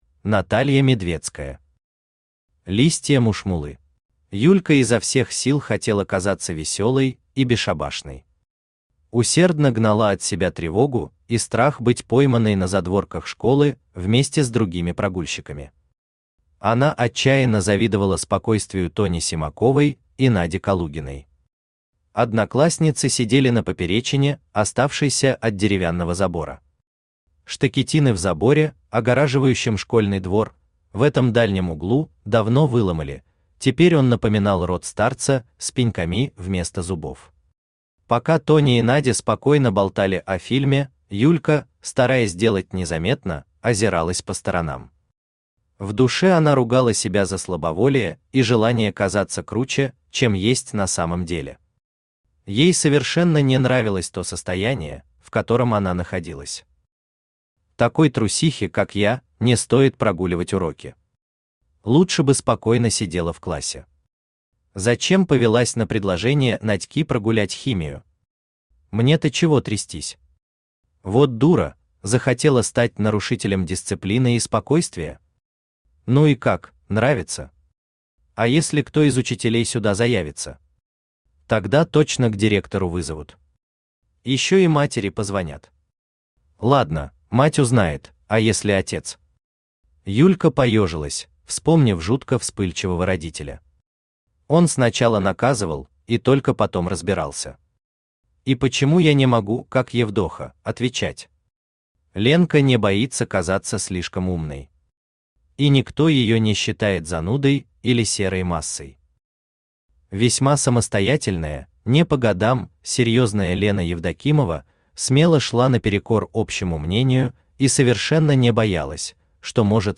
Аудиокнига Листья мушмулы | Библиотека аудиокниг
Aудиокнига Листья мушмулы Автор Наталья Брониславовна Медведская Читает аудиокнигу Авточтец ЛитРес.